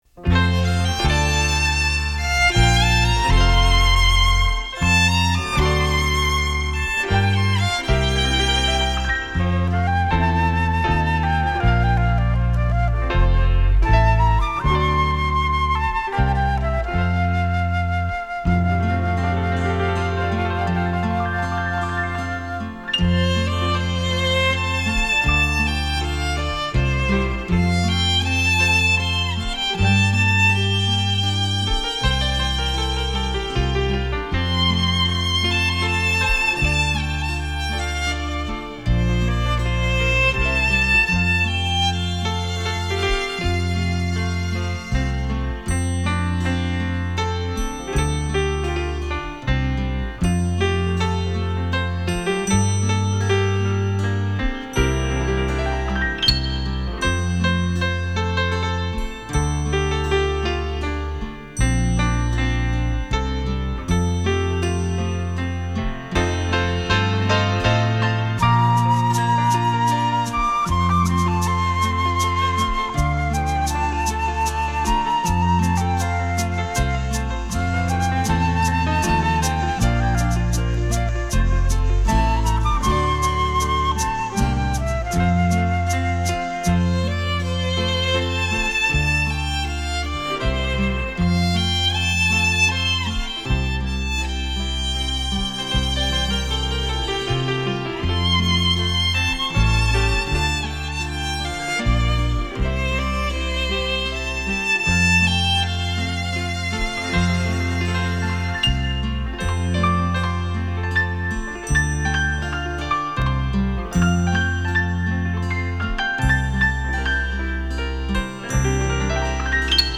钢琴的琴键与小提琴的琴弦
交织出浪漫美丽的生活情趣